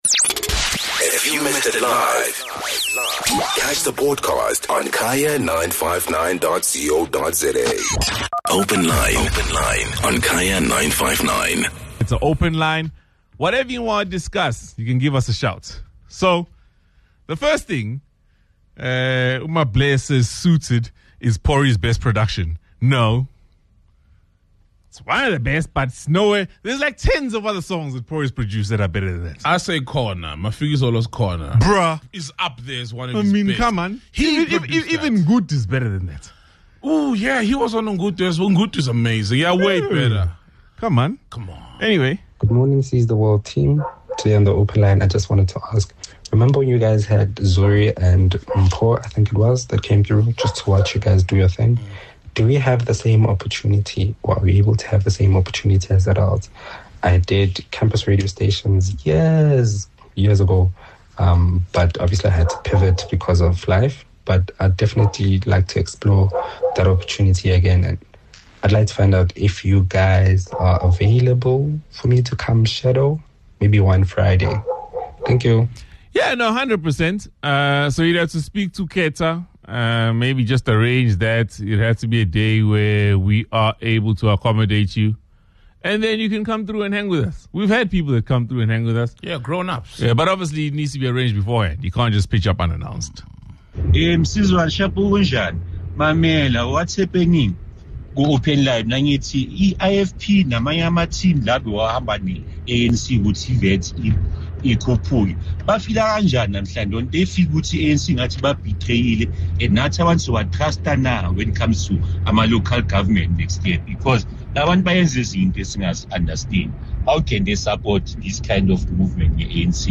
It's the Friday Open Line, and listeners shared their wide ranging questions, and comments.